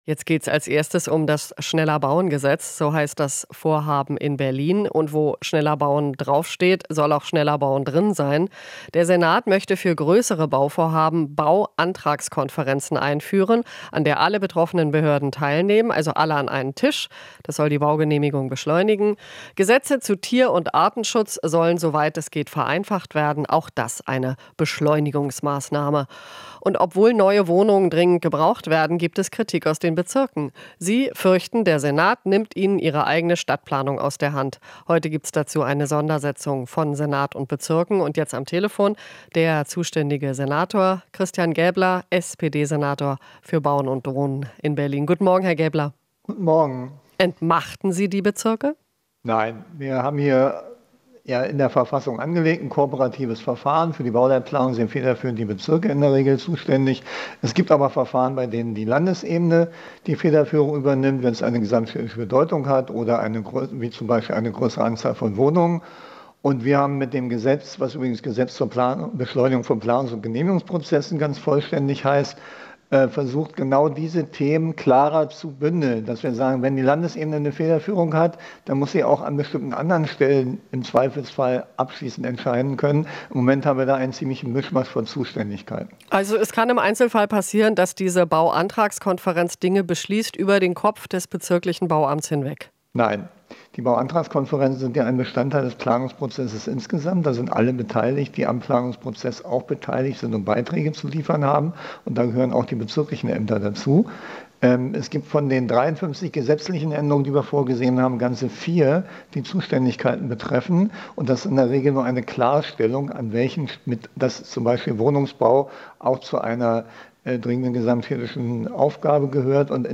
Interview - Bausenator Gaebler (SPD) weist Kritik der Bezirke zurück